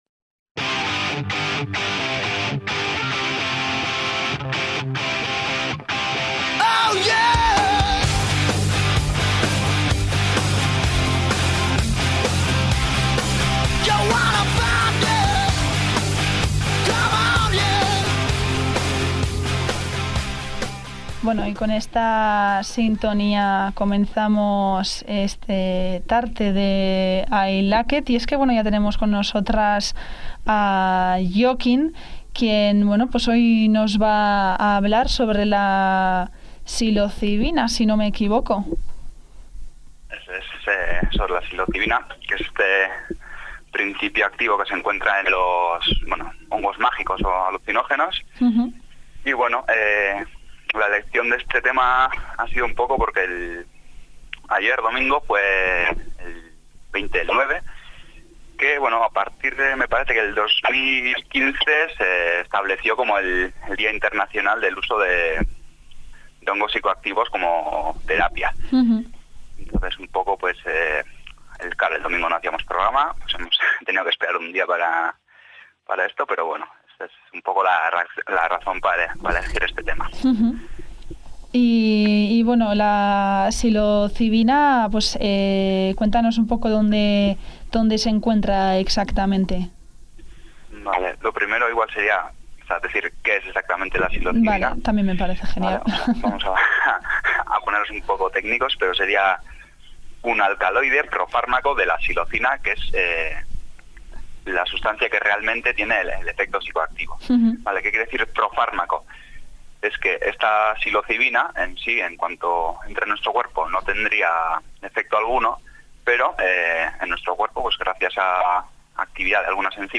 Aquí tenéis la entrevista con uno de los miembros de AiLaket! para saber más sobre la psilocibina: